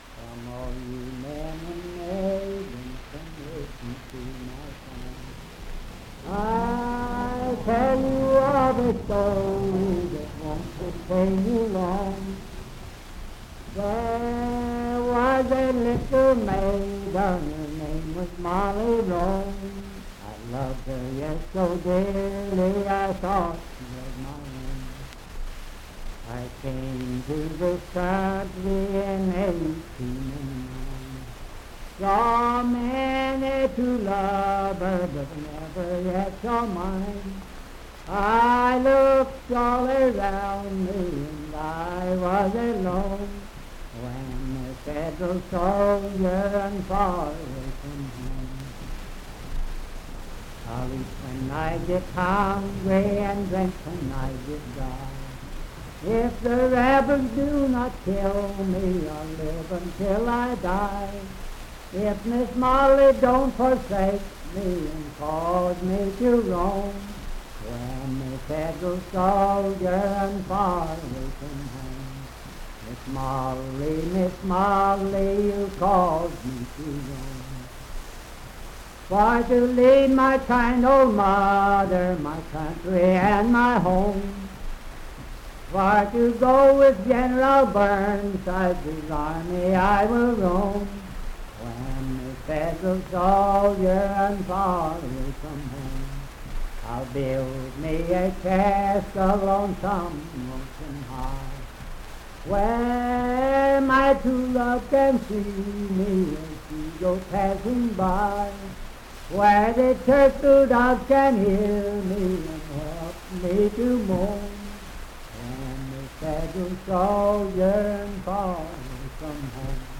Unaccompanied vocal music and folktales
Verse-refrain 5(4).
Voice (sung)